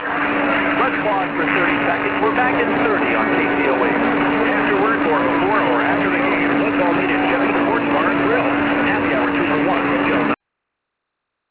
KTOE station ID